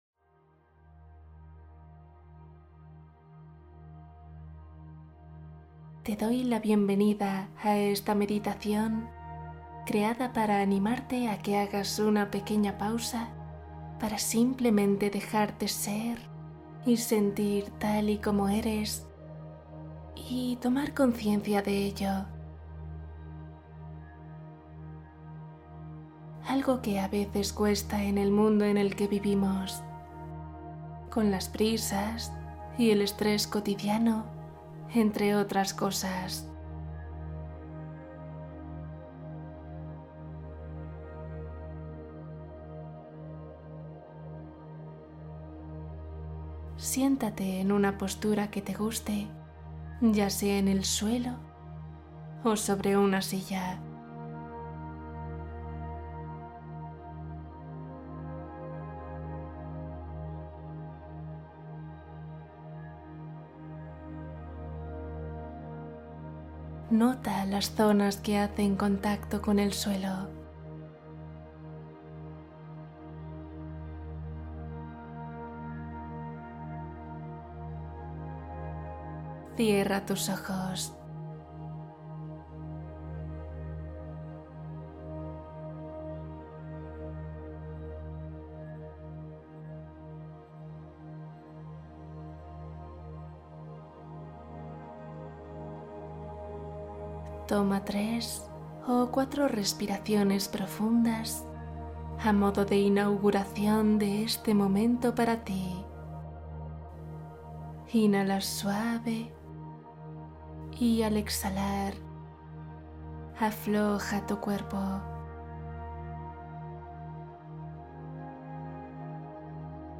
Sueño profundo ❤ Cuento y meditación para descansar profundamente